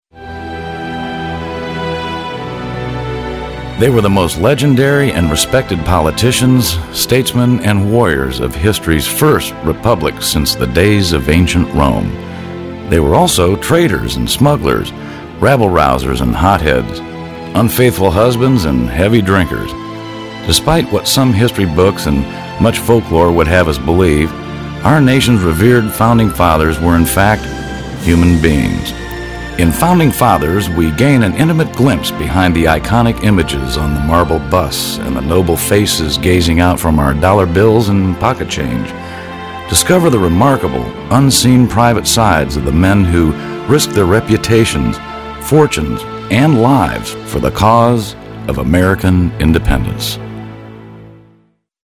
Baritone Voice; Voice Age 40-50; Documentaries, Corporate Narratives, Soft Sell Advertising.
Sprechprobe: Sonstiges (Muttersprache):